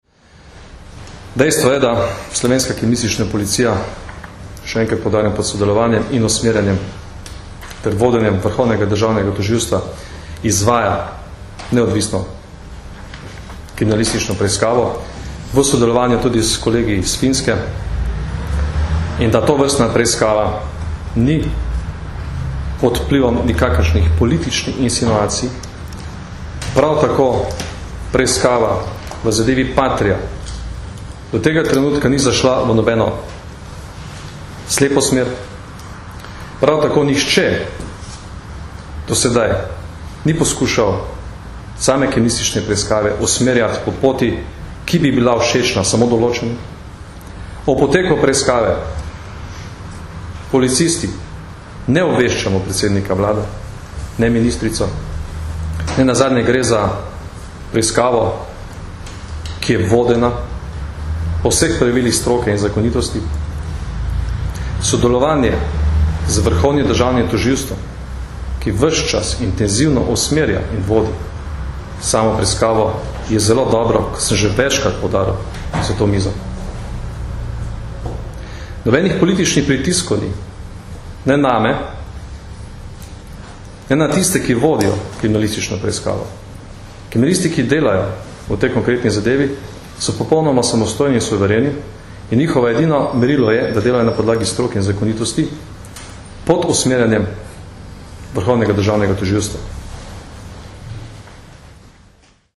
Zvočni posnetek izjave v. d. generalnega direktorja policije Janka Gorška (mp3)